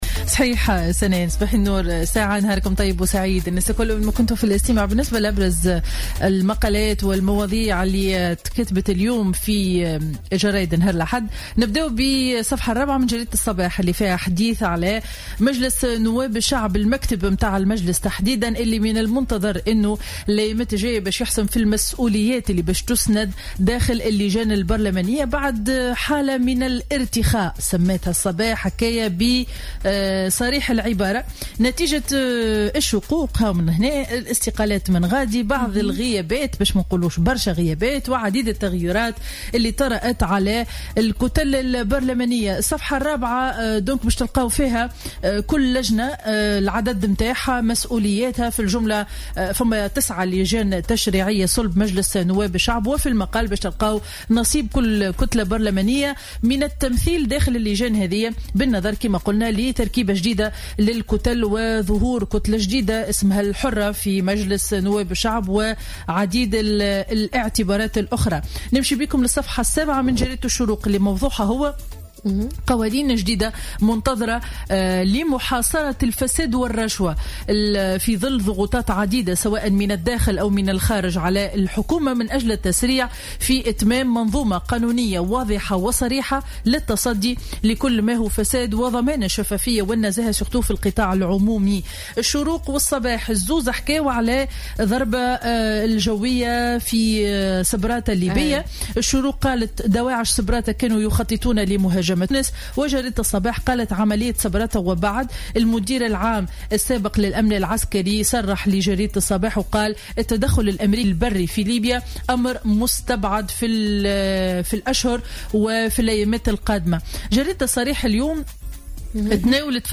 Revue de presse du Dimanche 21 Février 2016